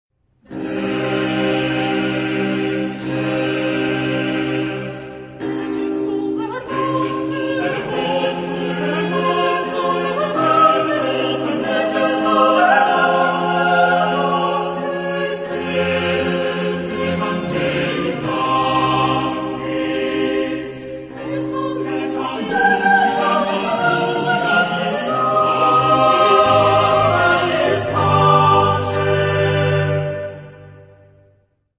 Genre-Style-Forme : Cantate ; Sacré
Caractère de la pièce : pieux ; fervent
Type de choeur : SSATB  (5 voix mixtes )
Solistes : SSB  (3 soliste(s))
Instruments : Basse continue ; Violon (2) ; Contrebasse de viole
Tonalité : do mineur
interprété par Dresdner Kammerchor dirigé par Hans-Christoph Rademann